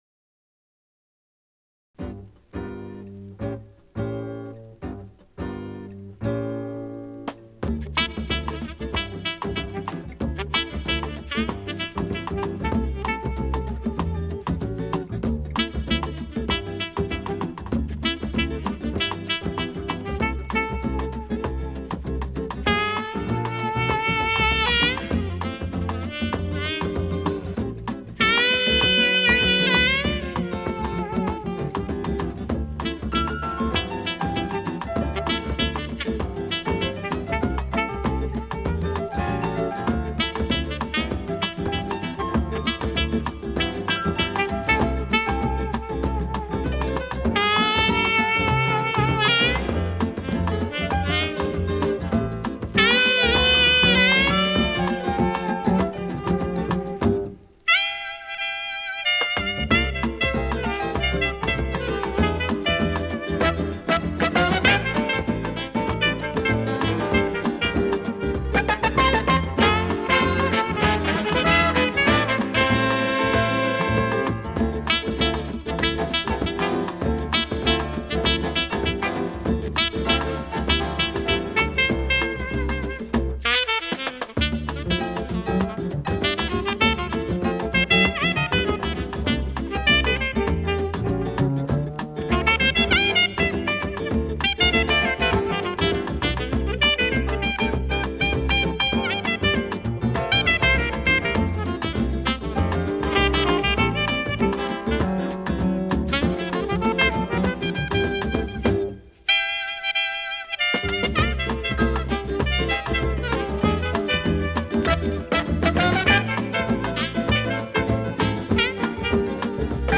Лучший мировой инструментал